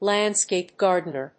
lándscape gàrdener